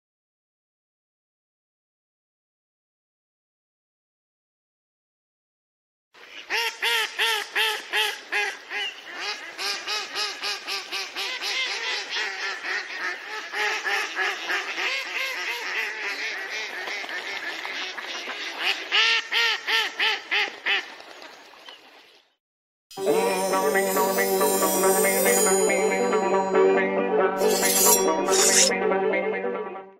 Enten Quaken Klingelton Für Android (Mp3) Und IPhone